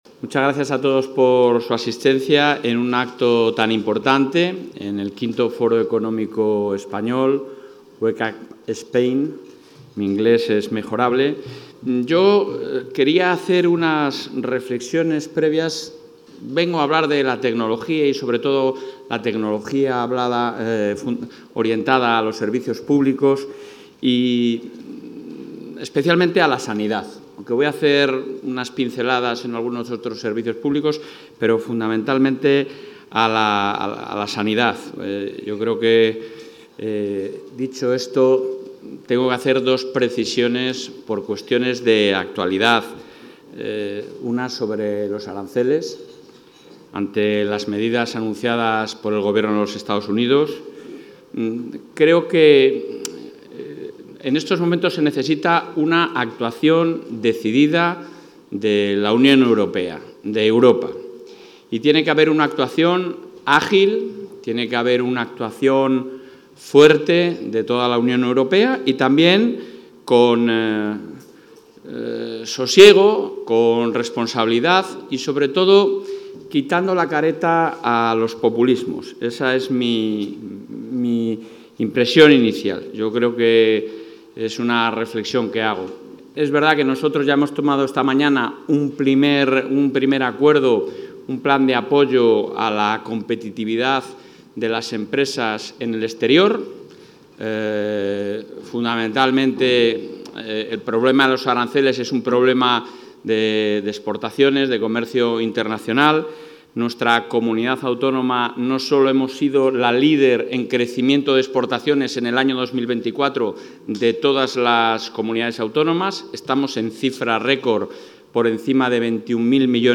Intervención del presidente.
El presidente de la Junta de Castilla y León, Alfonso Fernández Mañueco, ha participado hoy en Madrid en el Foro Wake Up Spain, organizado por 'El Español', donde ha destacado el liderazgo de la Comunidad en unos servicios públicos de calidad, modernos y apoyados en las tecnologías más avanzadas.